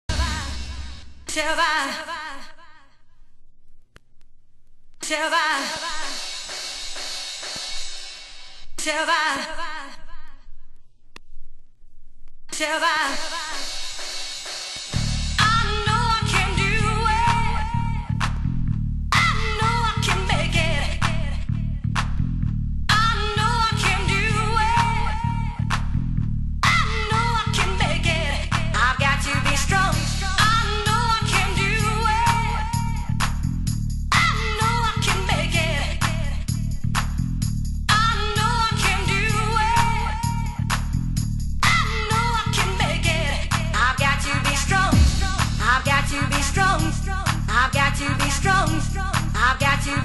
（不純物）/少しチリパチノイズ有